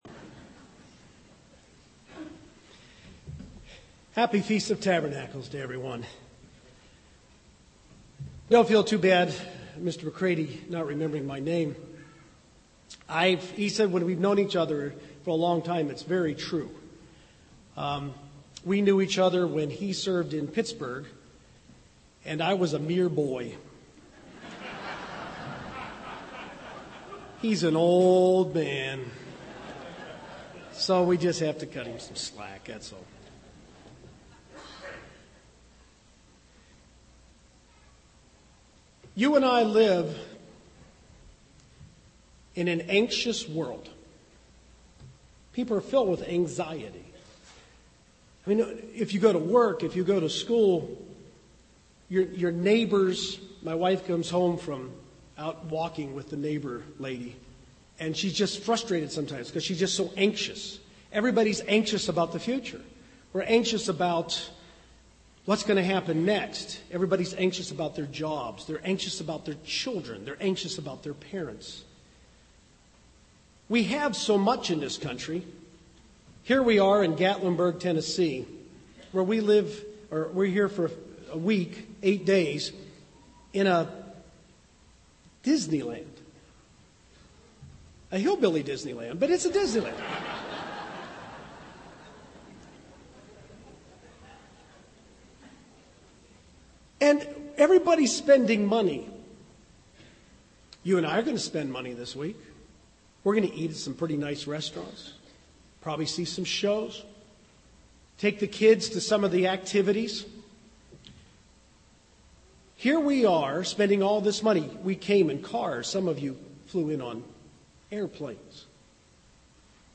This sermon was given at the Gatlinburg, Tennessee 2011 Feast site.